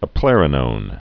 (əp-lĕrə-nōn)